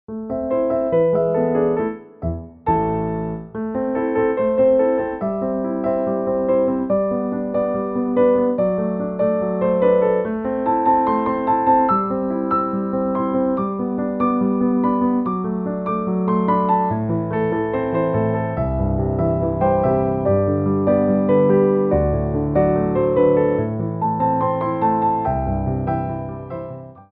2/4 (16x8)